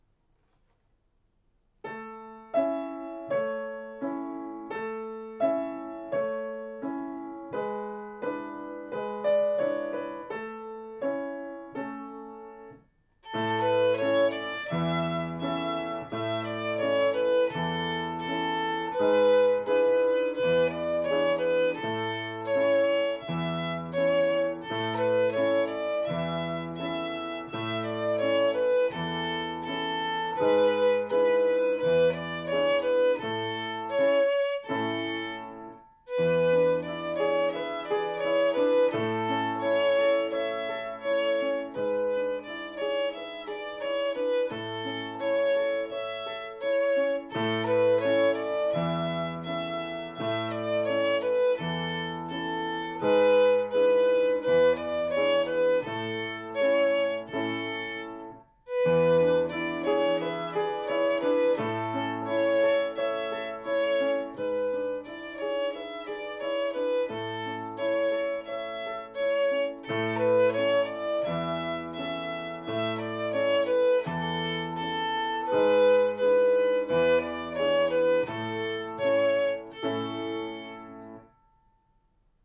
♪♪♪練習のヒント♪♪♪ 八分音符は慌てずに、一つ一つの音が豊かに響くように、ゆっくり丁寧に弾きます。四分音符のスタッカートは半分弾いて半分休む気持ちで、余韻を残しましょう。
ご自宅での練習用に録音しました。